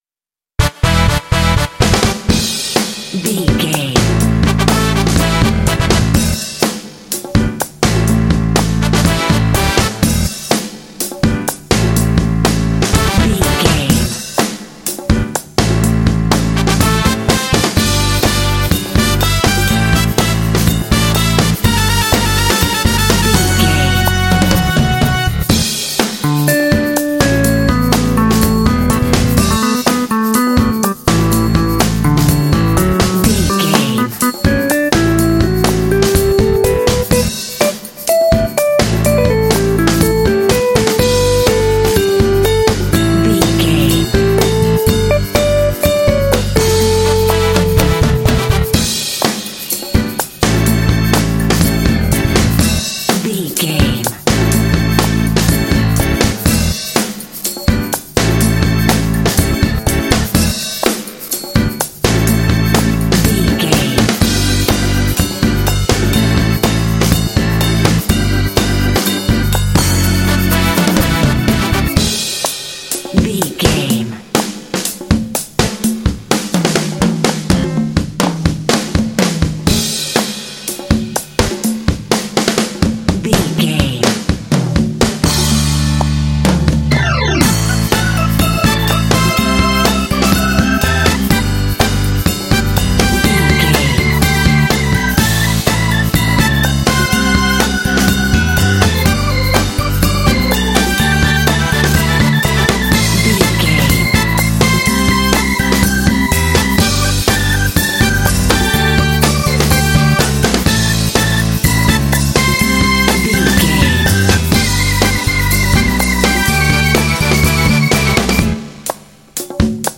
Uplifting
Aeolian/Minor
funky
smooth
groovy
driving
brass
piano
drums
bass guitar
saxophone
electric guitar
electric organ
Funk
soul
motown